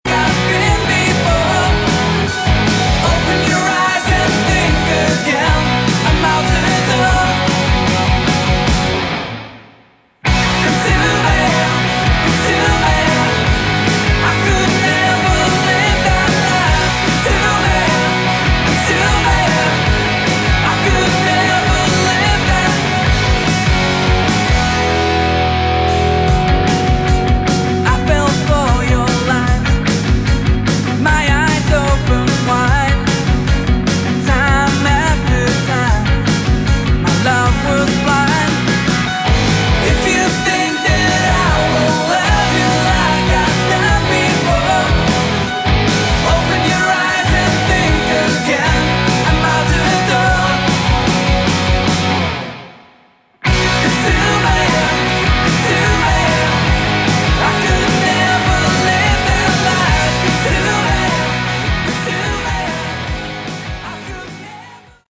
Category: Melodic Rock
Bass
Lead Vocals, Guitar
Drums
Keyboards